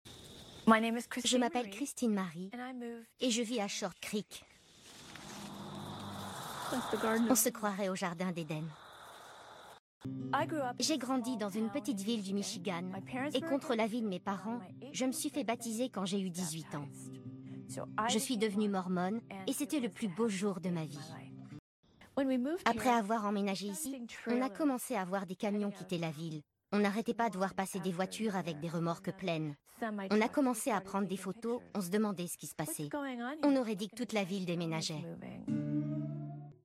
Voice-over femme documentaire Discovery
J'ai une voix jeune, légère, cristalline et pétillante...